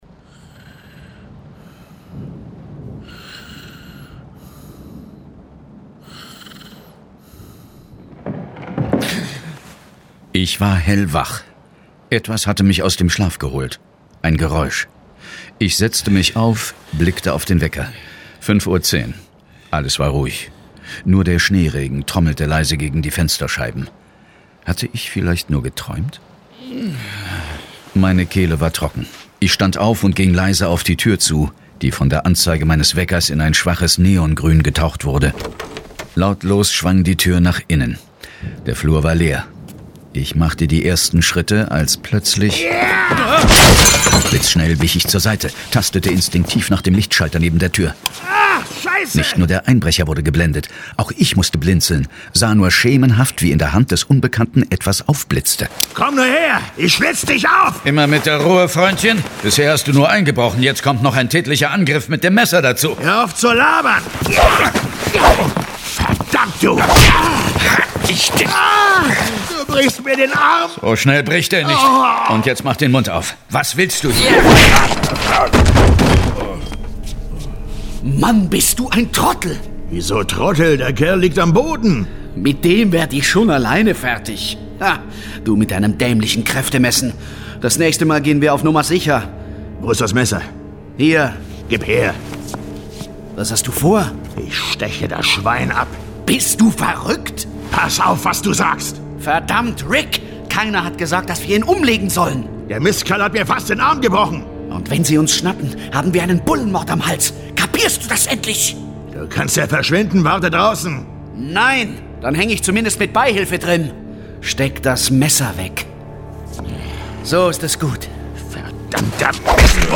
John Sinclair - Folge 43 Ich flog in die Todeswolke. Hörspiel.